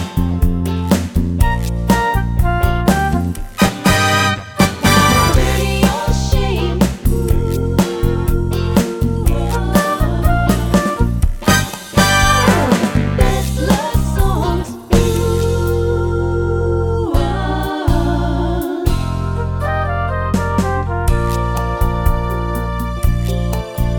Minus Rhodes Piano Pop (1970s) 3:50 Buy £1.50